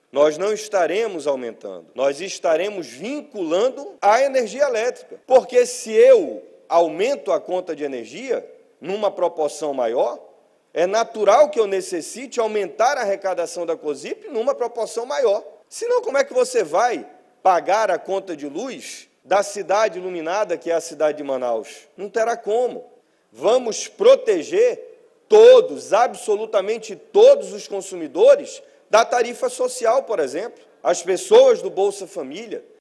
Já o vereador Marcelo Serafim, da base do Prefeito, diz que não se trata de um reajuste, mas sim uma modernização.